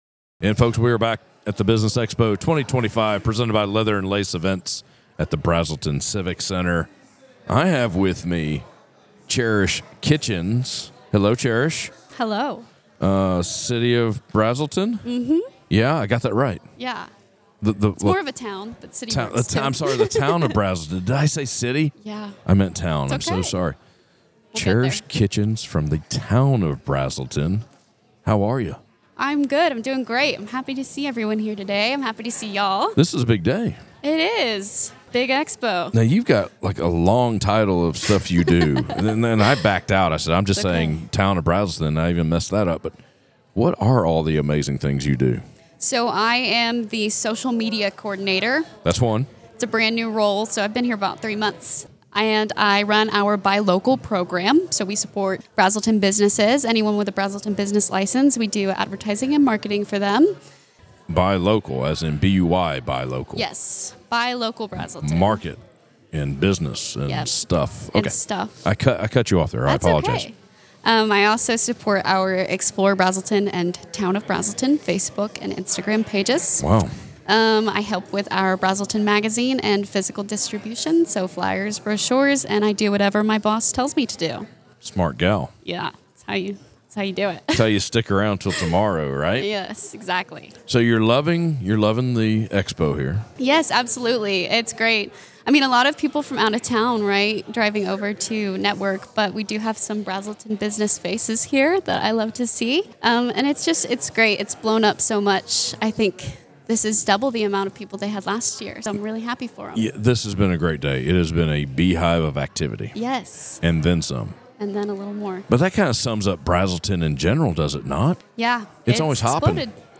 Northeast Georgia Business RadioX – the official Podcast Studio of the Business Expo 2025